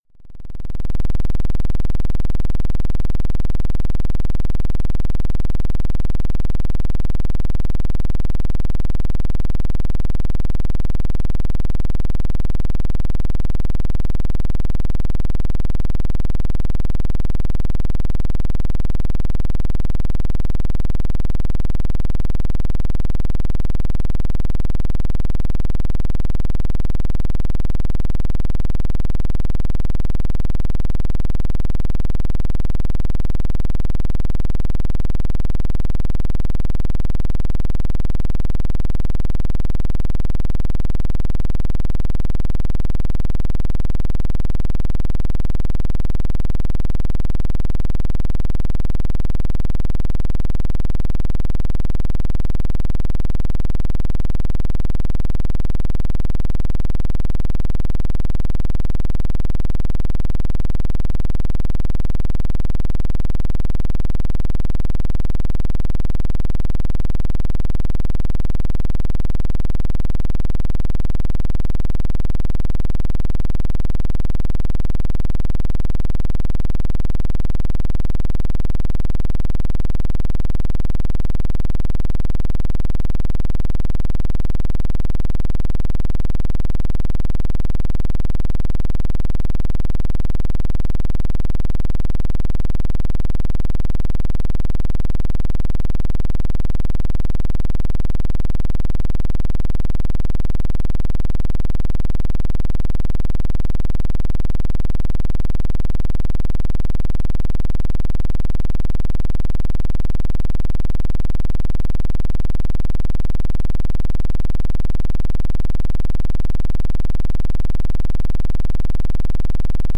09. PEMF 10 Hz - Cell Growth and Regeneration Square.mp3